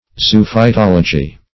Search Result for " zoophytology" : The Collaborative International Dictionary of English v.0.48: Zoophytology \Zo*oph`y*tol"o*gy\ (?; 277), n. [Zoophyte + -logy: cf. F. zoophytologie.] The natural history zoophytes.